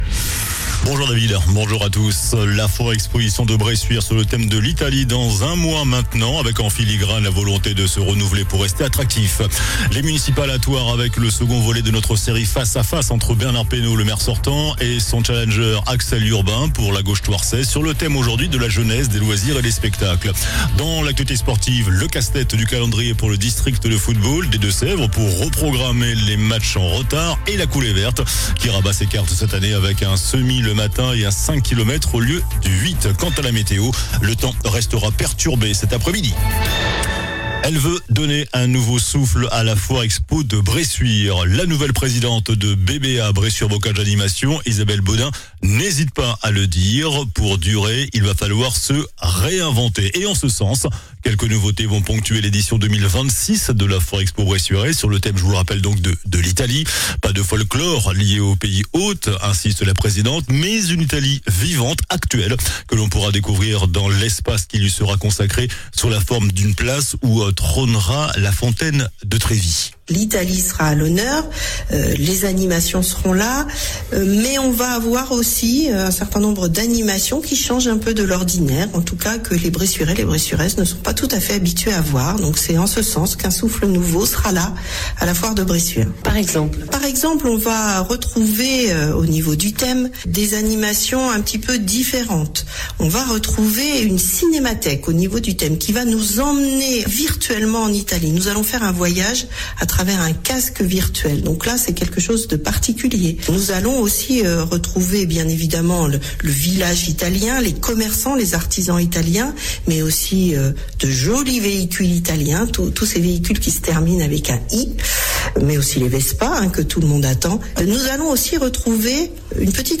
JOURNAL DU MERCREDI 18 FEVRIER ( MIDI )